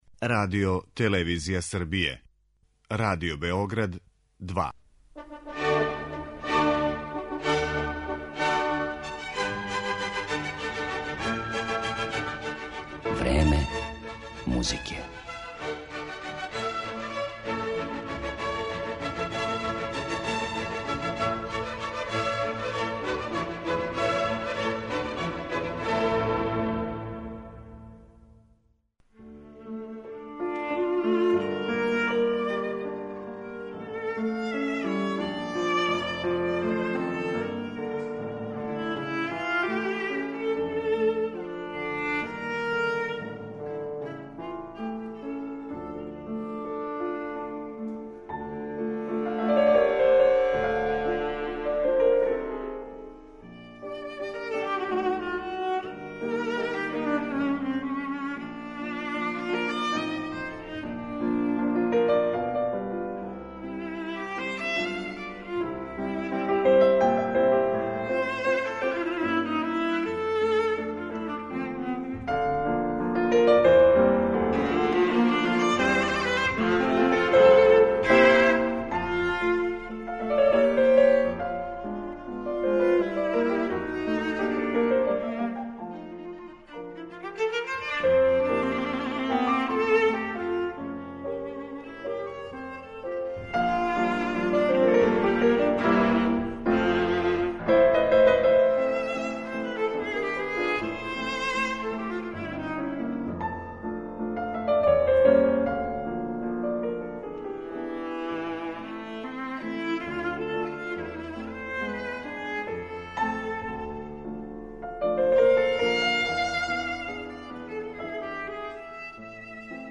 Данашња емисија посвећена је једном од наjвећих виртуоза данашњице на виоли. Славна немачка солисткиња Табеа Цимерман изводи композиције Роберта Шумана, Ђерђа Лигетија, Јоханеса Брамса и Фрица Крајслера.